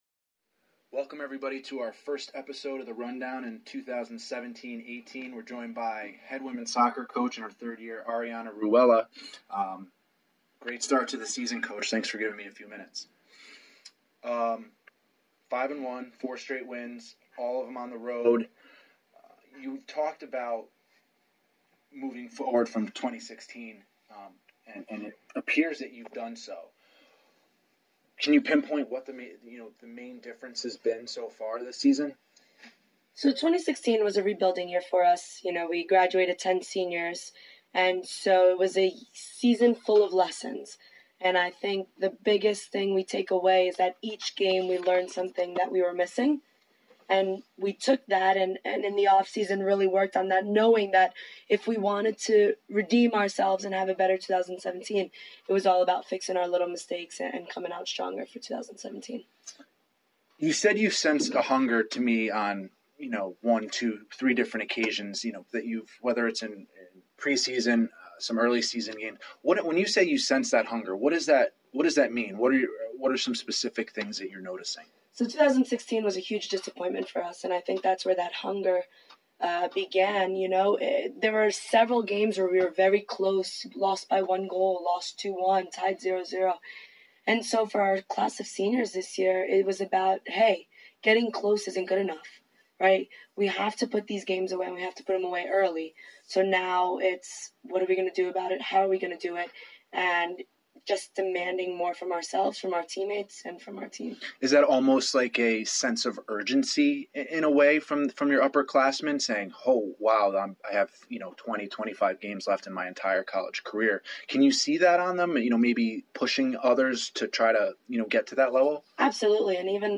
Interview_003_1.mp3